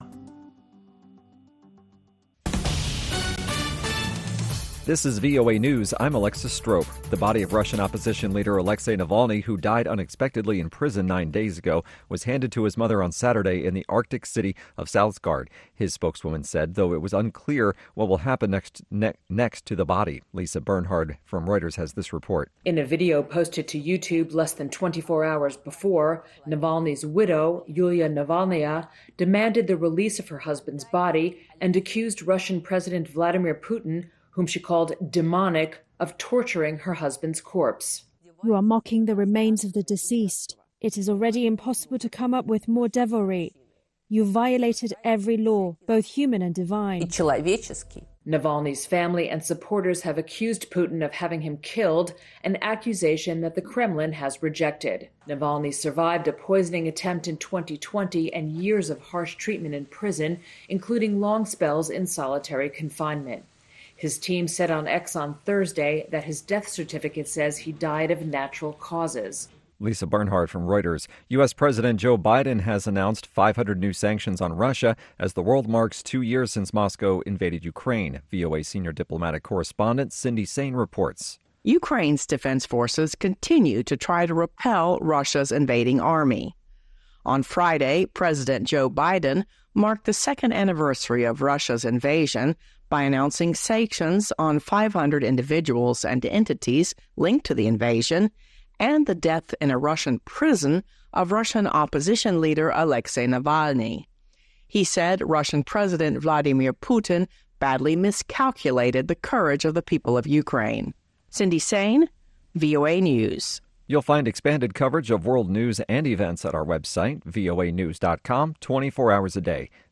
Around the clock, Voice of America keeps you in touch with the latest news. We bring you reports from our correspondents and interviews with newsmakers from across the world.